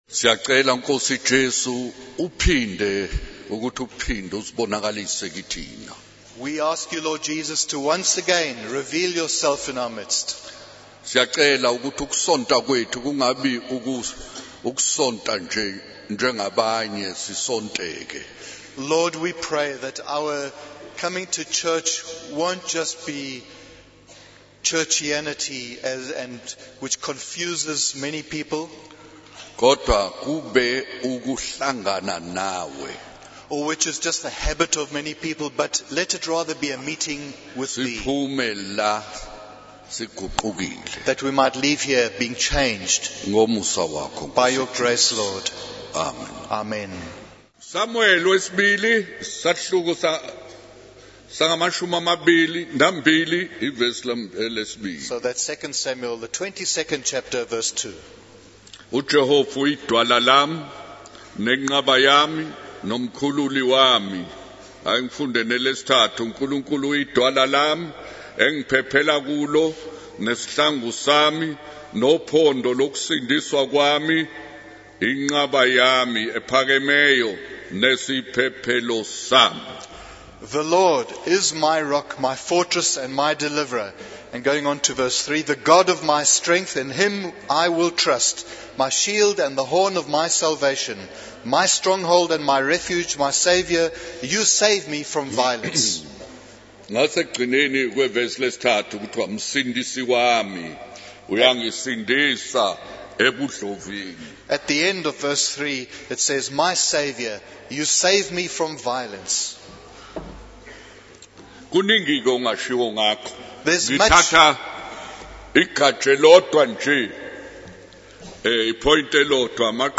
In this sermon, the preacher discusses the importance of family as a protection and shelter provided by God. He emphasizes the need to honor and respect parents and live in obedience to their teachings.